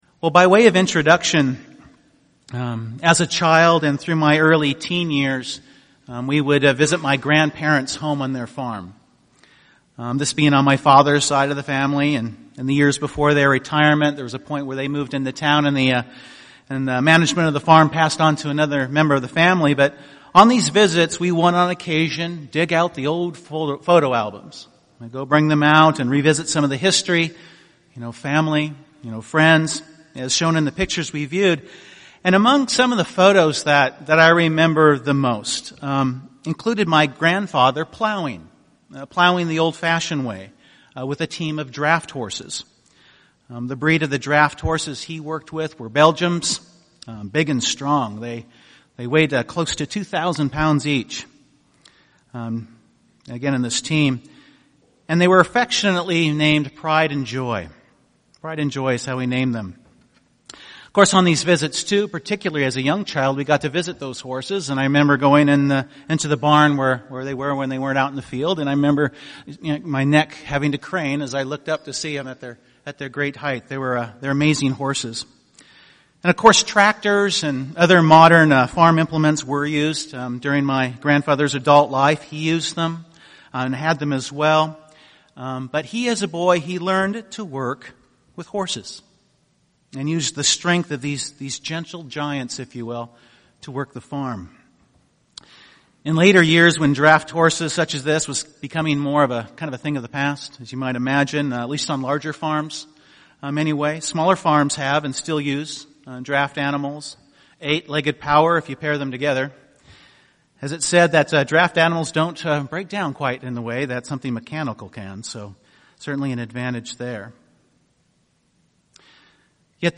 The bible shares many references to the term yoked. This sermon focuses on some examples of how this word is used in scripture, what “unequally yoked” means (2 Cor. 6:14), and the true yoke we are to come under (Matt. 11:29) as part of what the Days of Unleavened Bread picture.